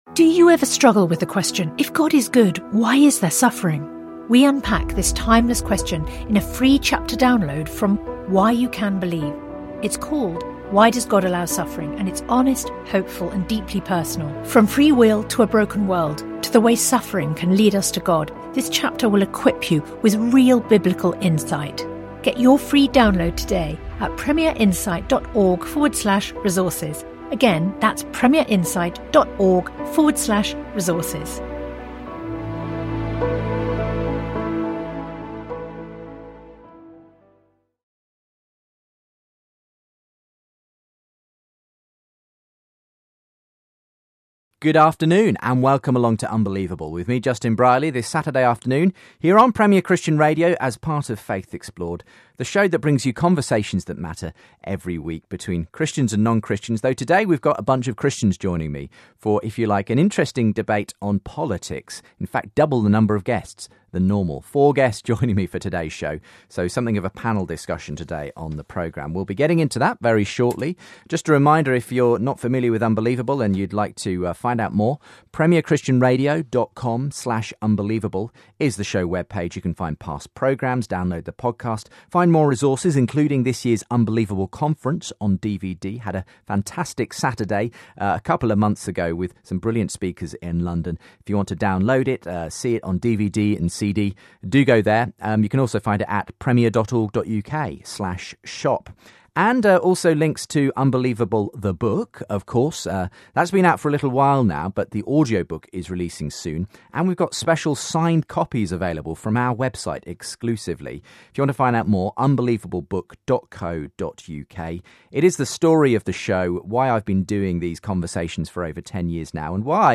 Christianity, Religion & Spirituality
panel discussion